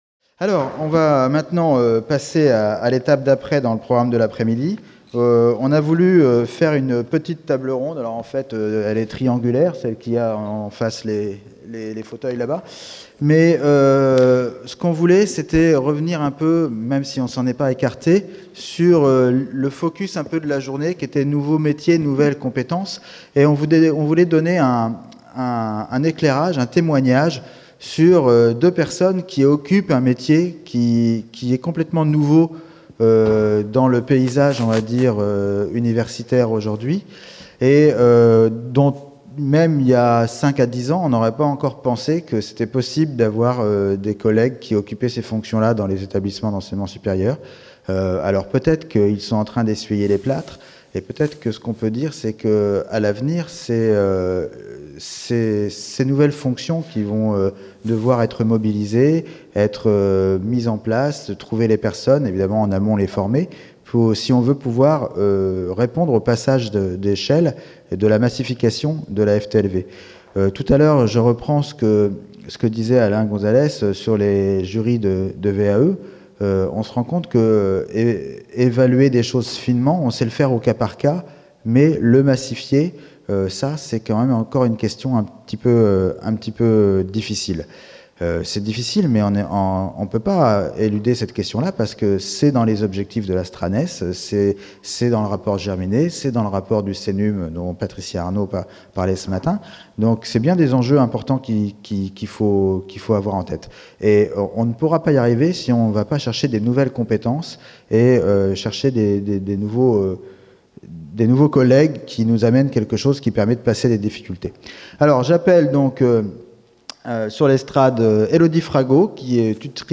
04 Table ronde autour des Ressources Humaines dans le séminaire numérique et formation toute au long de la vie | Canal U